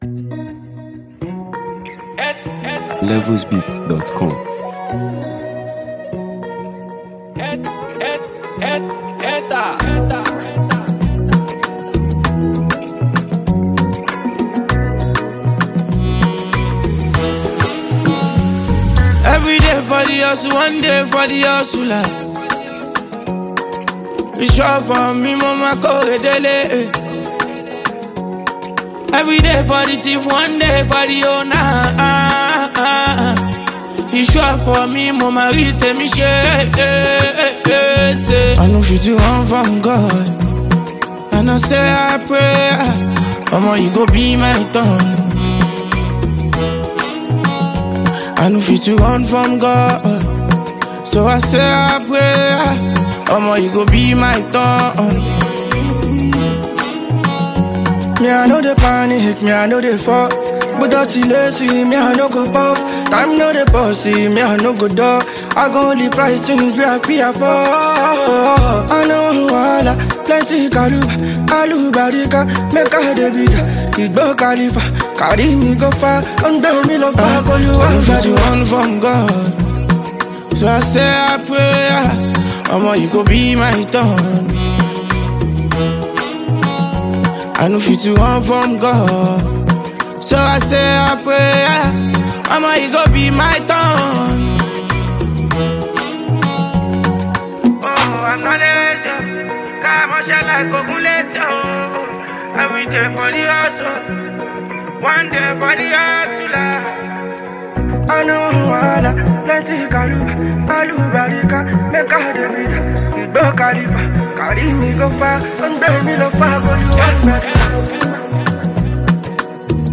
Prominent Nigerian street-pop sensation and songwriter